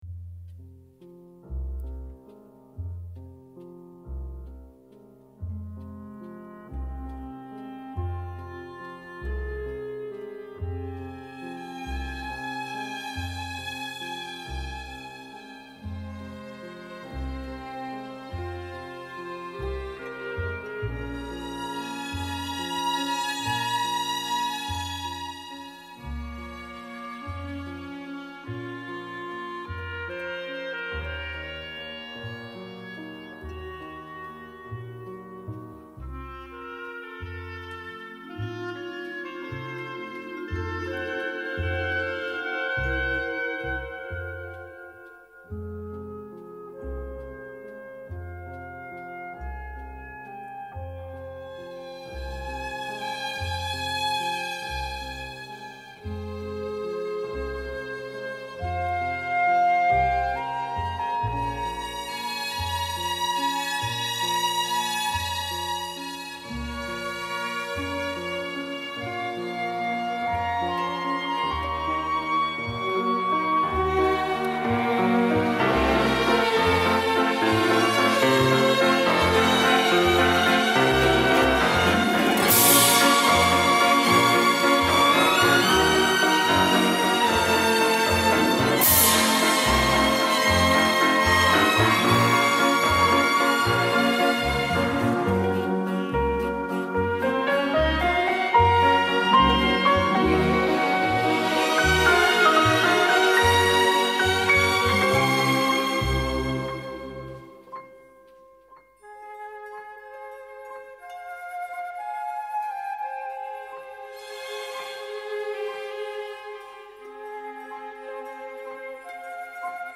Великолепный вальс